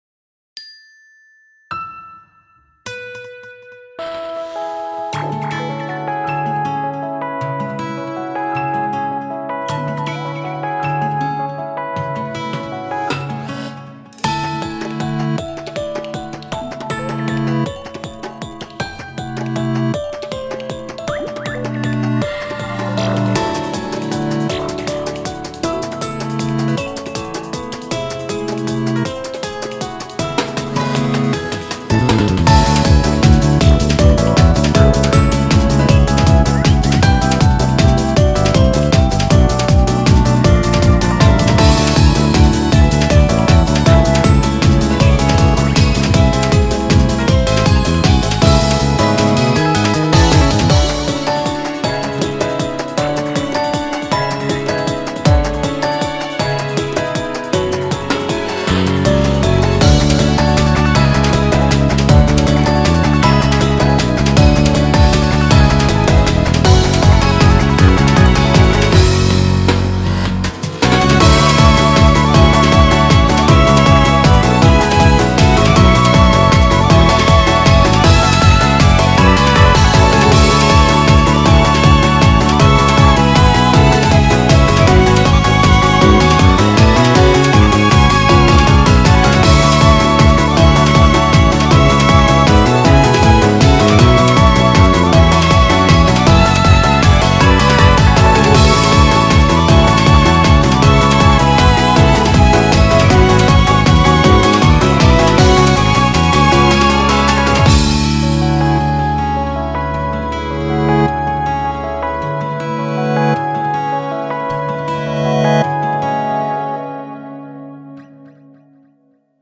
BPM158
Audio QualityPerfect (High Quality)
Comments[WORLD/ELECTRONICA]